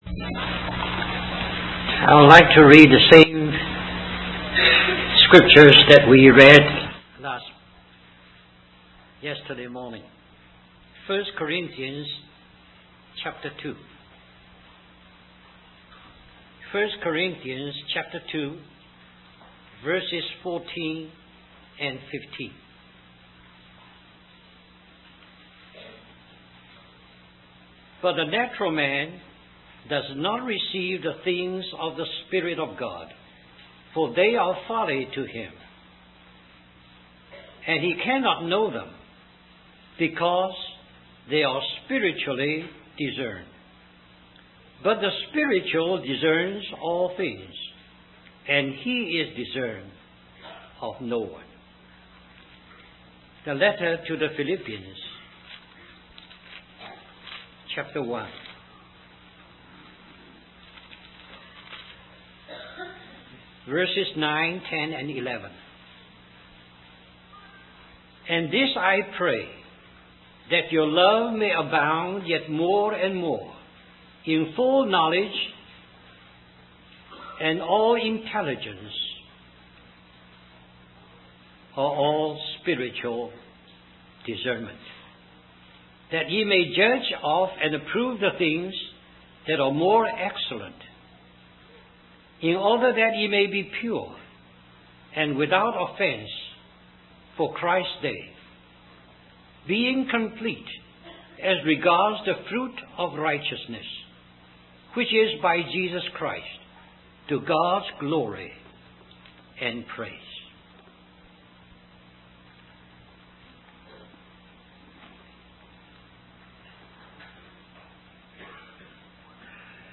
In this sermon, the speaker emphasizes the importance of spiritual exercise for believers. He compares the physical exercise done in the gymnasium to the spiritual exercise that believers should engage in.